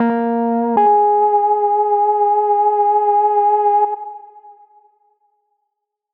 フリー効果音：負け
負けた時のちょっとシュール系の効果音です！ミスシーンやコミカルなシーンにぴったりです！
lost.mp3